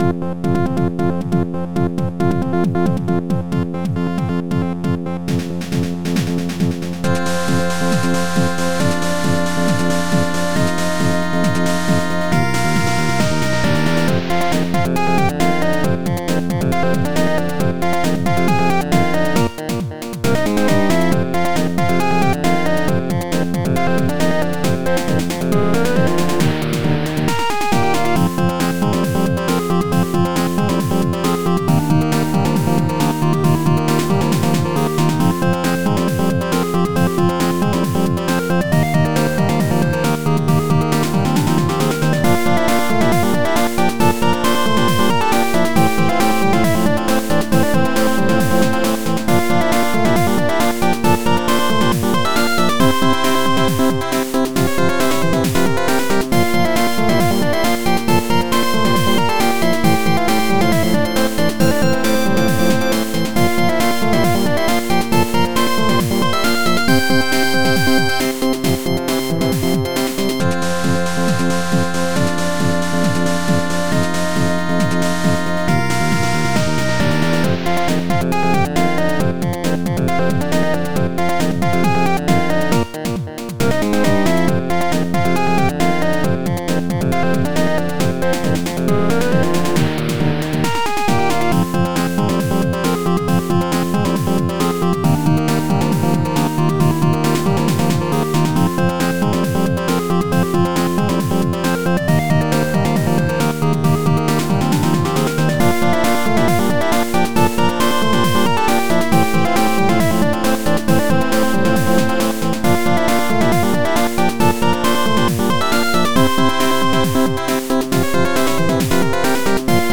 136bpm